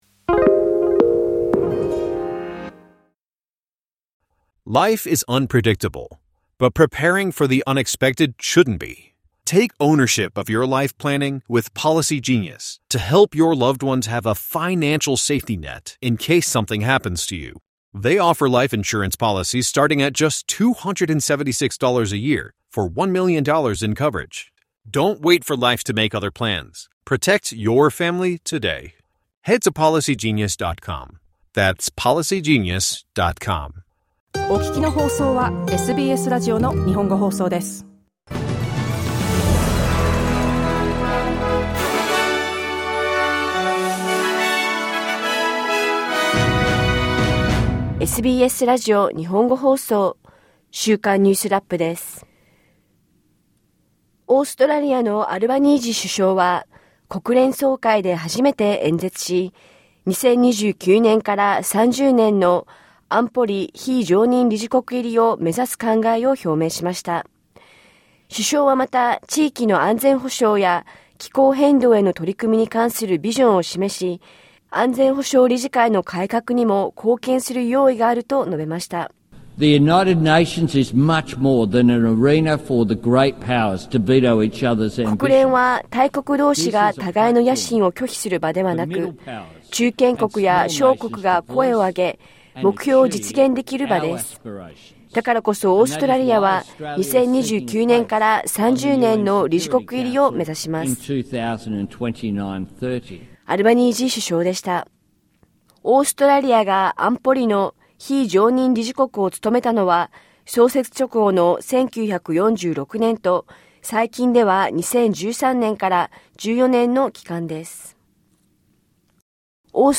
デンマークの首相が、過去にグリーンランドの先住民女性や少女に対し行われた、強制的な避妊措置について、歴史的な謝罪をしました。1週間を振り返るニュースラップです。